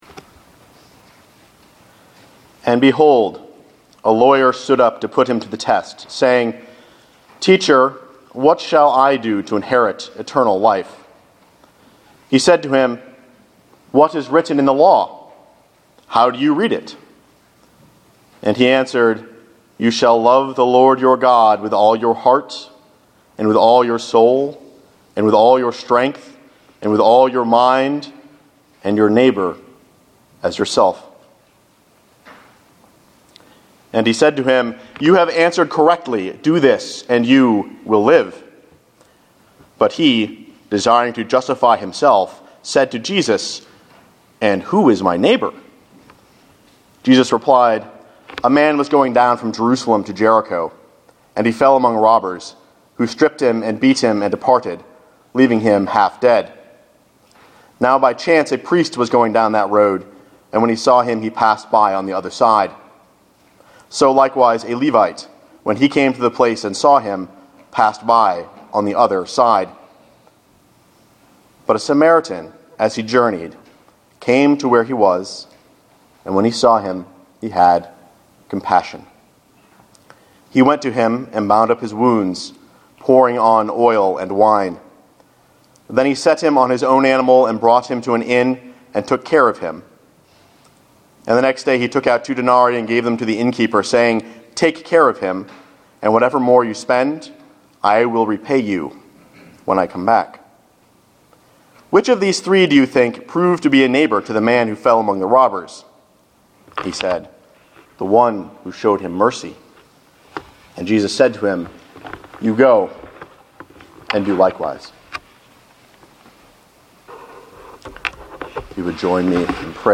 A sermon preached July 14, 2013, at Union Congregational Church UCC in North Reading, MA.